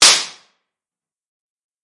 超音速扣杀1
描述：使用Audacity创建的超音速子弹SFX。
Tag: 飕飕 子弹